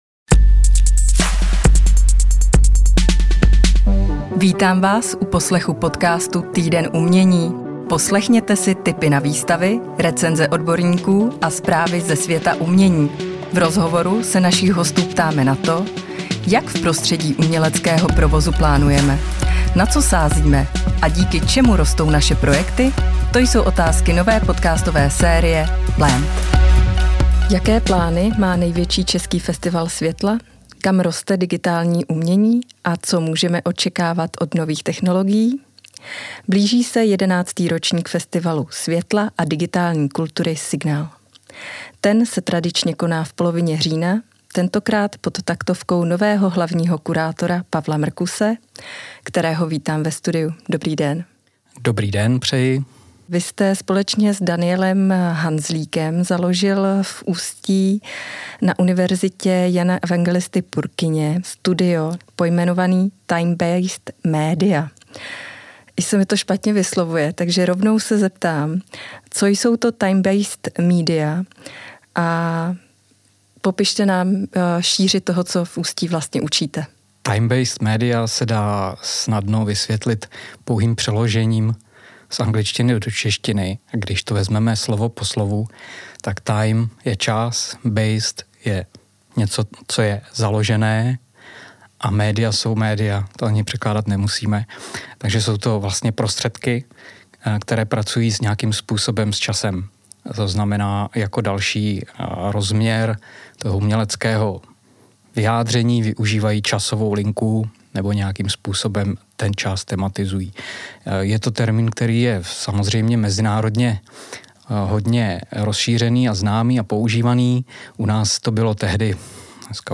V rozhovoru nám přiblížil nový tříletý programový cyklus Signal festivalu pod souhrnným názvem Ekosystémy, ve kterých se kurátorsky zaměřuje se umělecký průzkum environmentálních, kulturních či vědeckotechnických vazeb v naší společnosti.